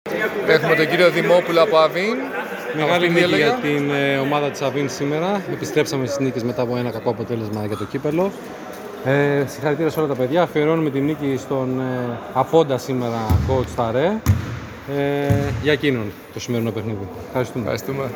GAME INTERVIEWS: